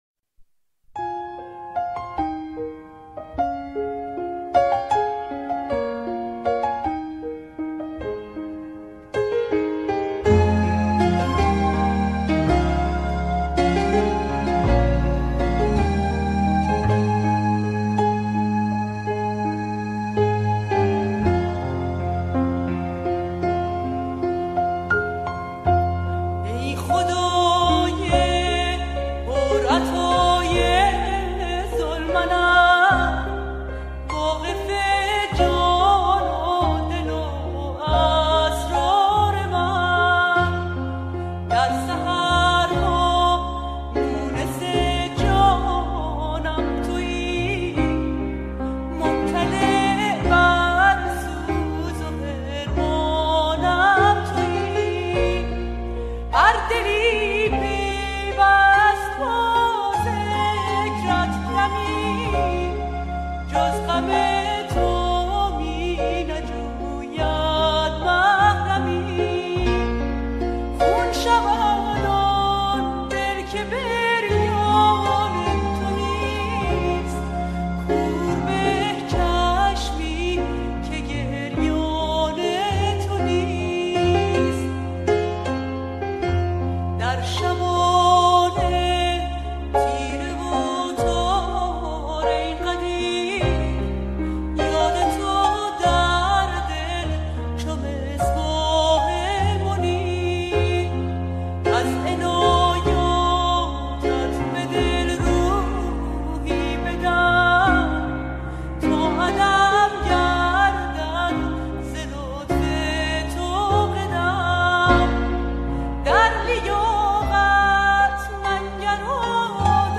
در این قسمت می‌توانید تعدادی از مناجات‌های بهائی را به همراه موسیقی بشنوید.